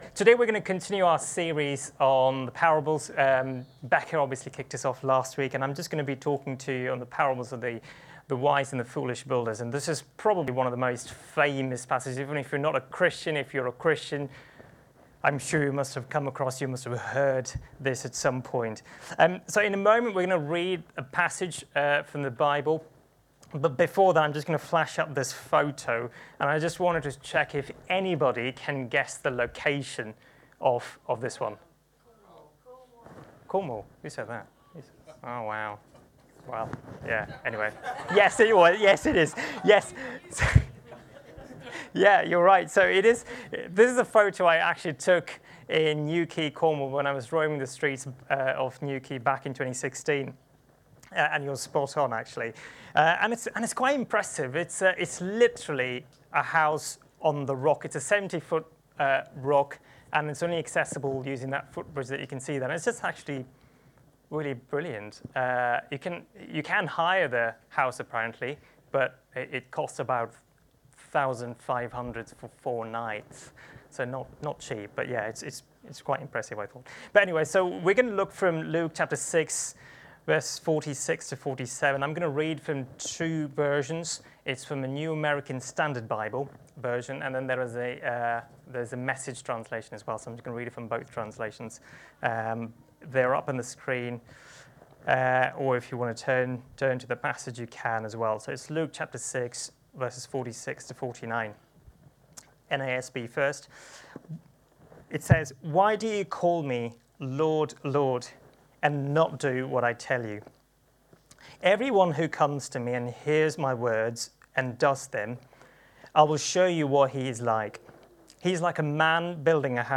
Download Parable of the wise and foolish builders | Sermons at Trinity Church